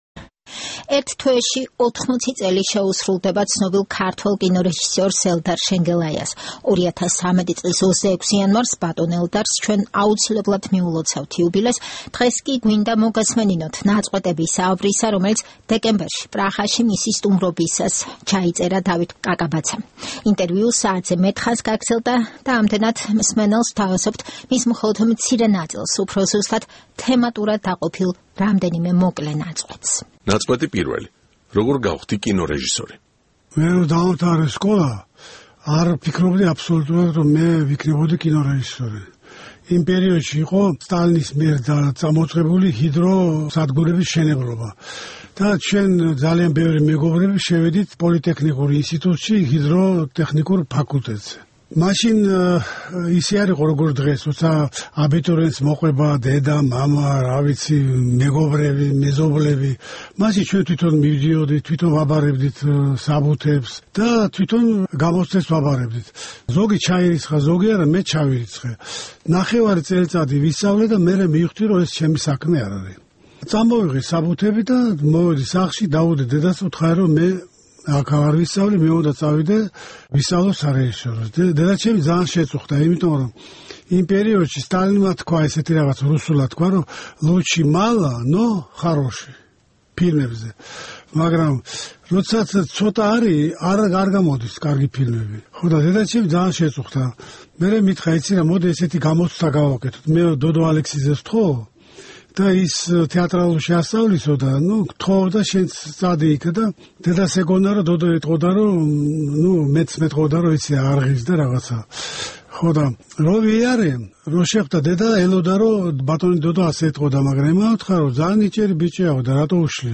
საუბარი ელდარ შენგელაიასთან